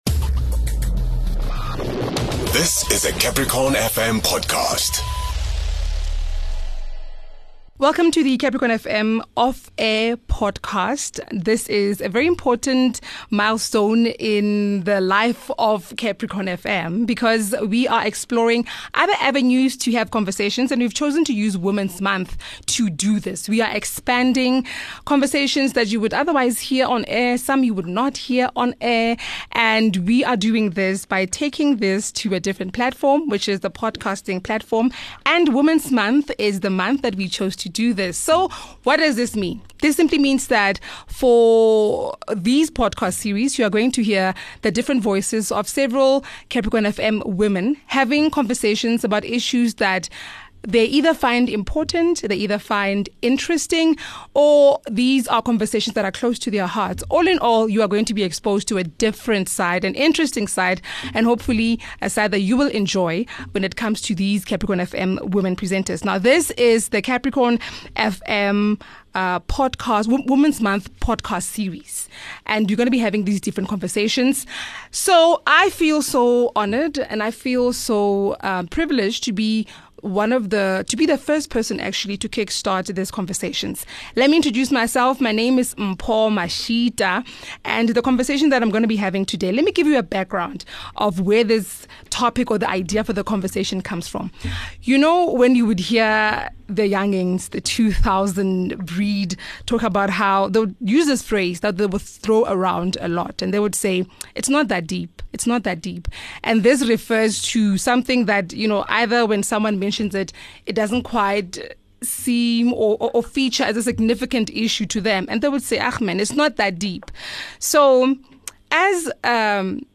The Capricorn FM Women's Month Podcast Series are extended conversations hosted by Capricorn FM's female Presenters.